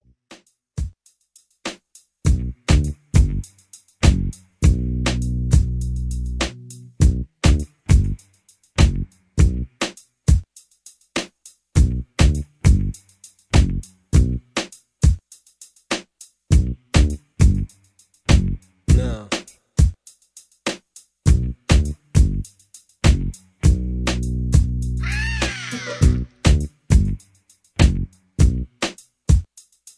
rap, r and b, hip hop